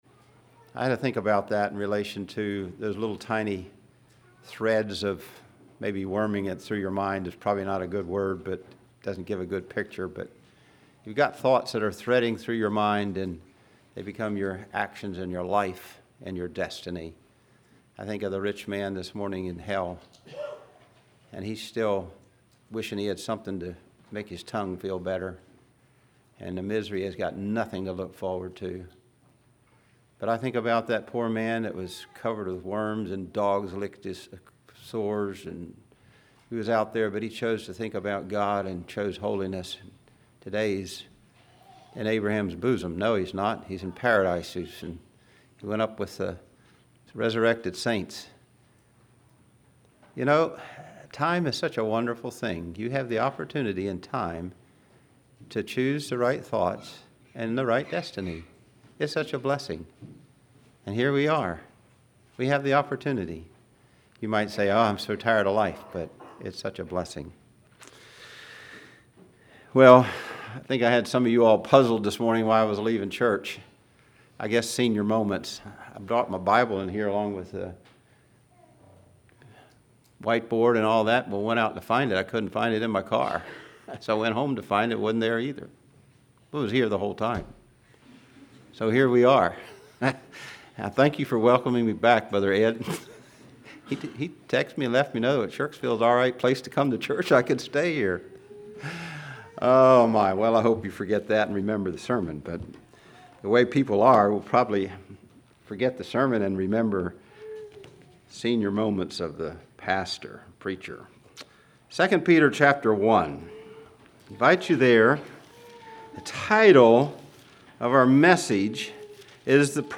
Congregation: Shirksville